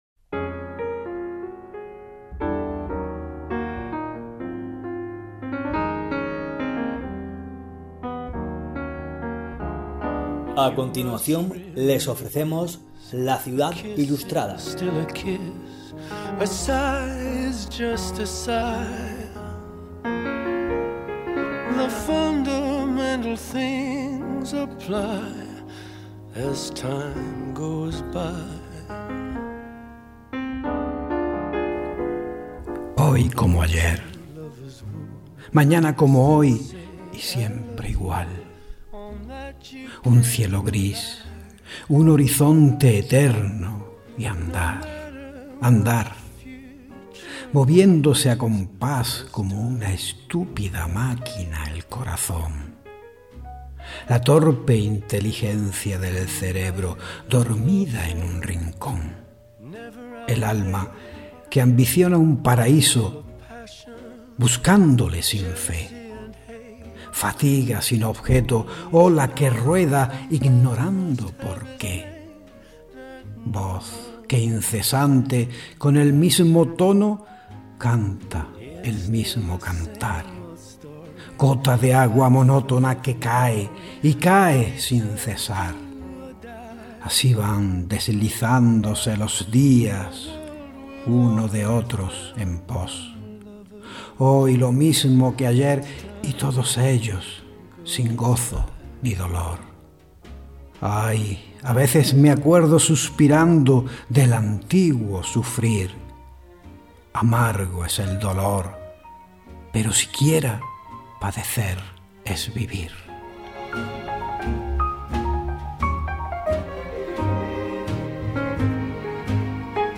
Entrevista
En la imagen, un momento de la entrevista.